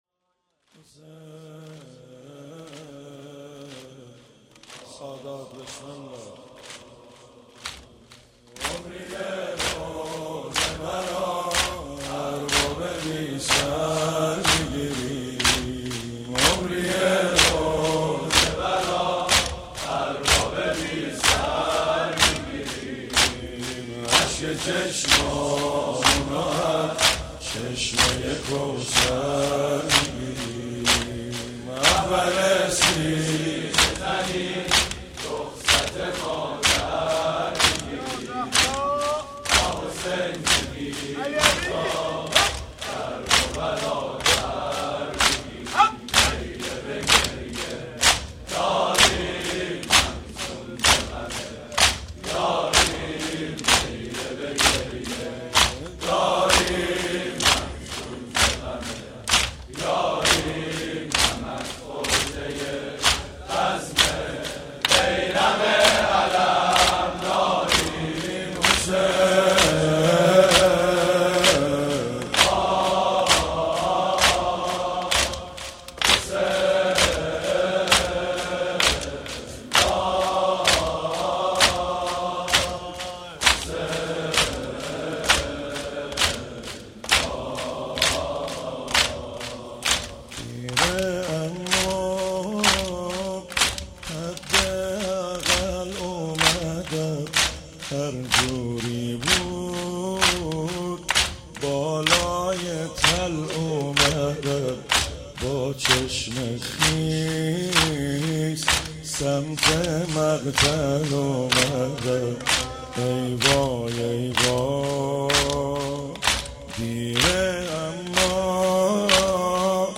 مداحی شب دهم عاشورا محرم 99